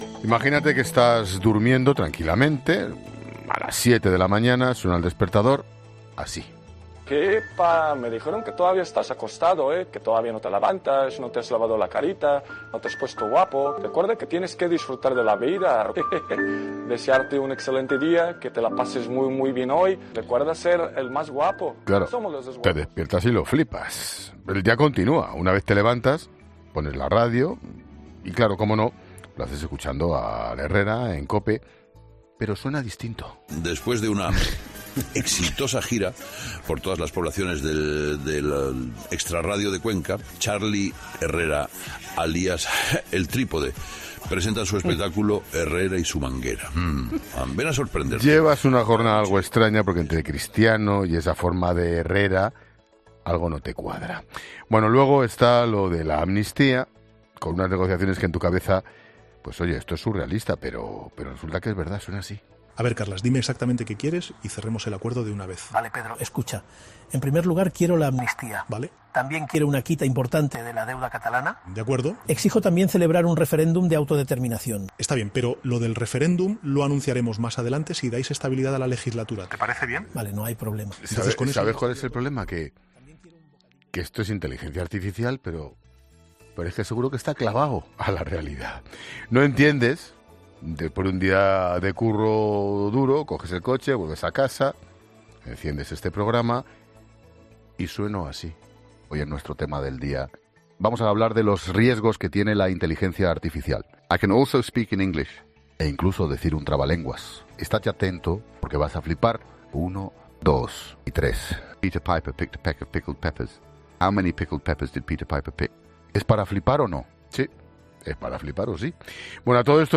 Escucha cómo Expósito alucina al escuchar un audio de Juanma Castaño sobre su futuro profesional
Hasta el punto que el comunicador de COPE reconocía no saber diferenciar se se trataba de unas declaraciones generadas por Inteligencia Artificial o no.